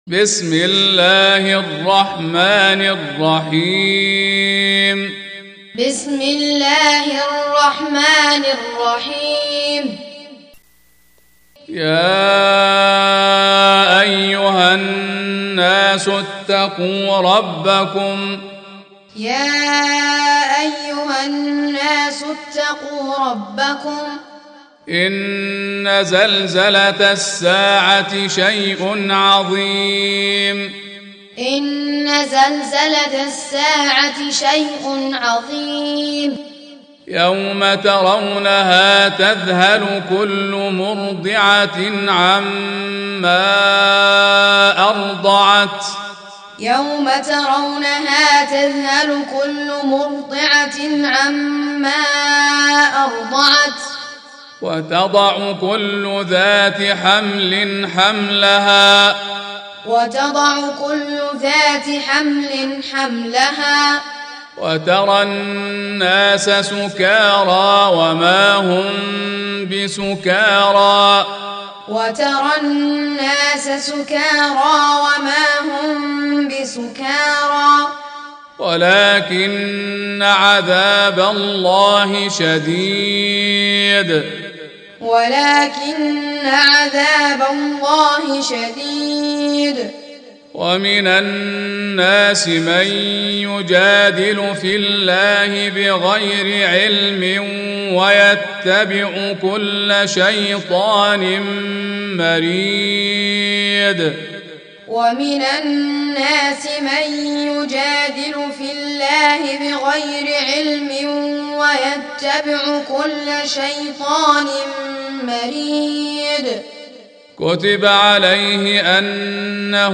Surah Repeating تكرار السورة Download Surah حمّل السورة Reciting Muallamah Tutorial Audio for 22. Surah Al-Hajj سورة الحج N.B *Surah Includes Al-Basmalah Reciters Sequents تتابع التلاوات Reciters Repeats تكرار التلاوات